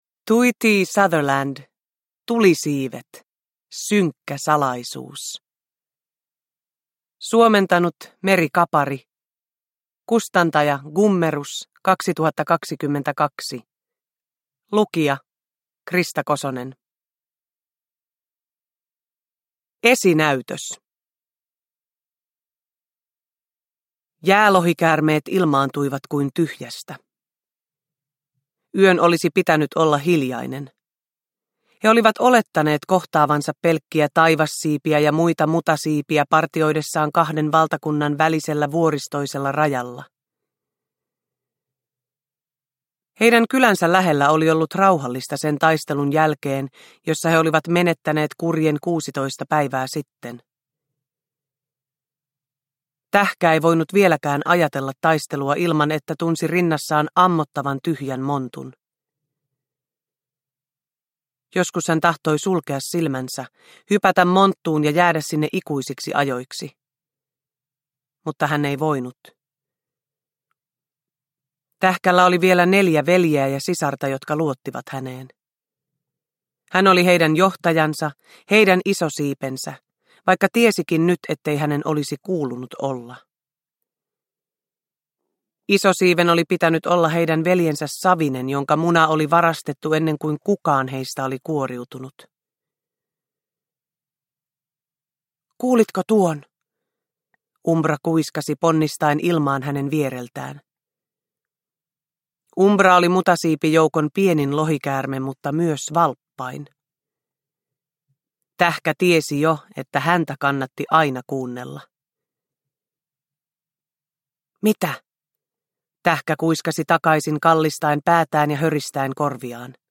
Synkkä salaisuus – Ljudbok
Uppläsare: Krista Kosonen